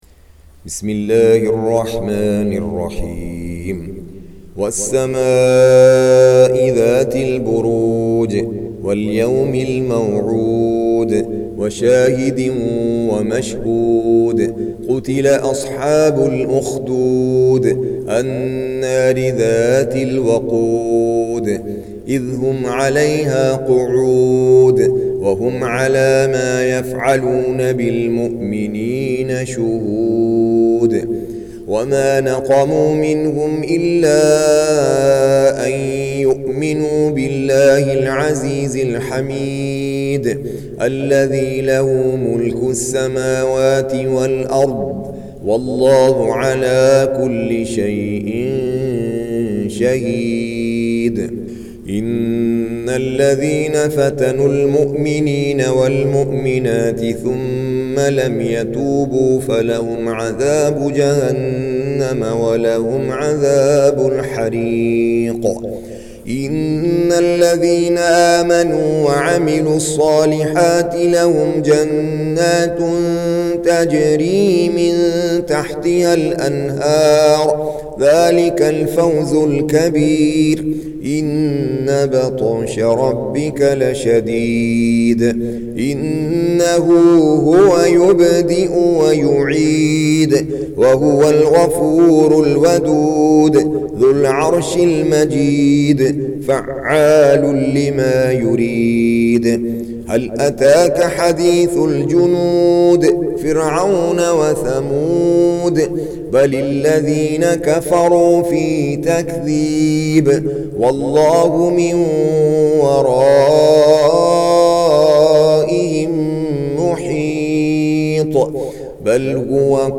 Surah Sequence تتابع السورة Download Surah حمّل السورة Reciting Murattalah Audio for 85. Surah Al-Bur�j سورة البروج N.B *Surah Includes Al-Basmalah Reciters Sequents تتابع التلاوات Reciters Repeats تكرار التلاوات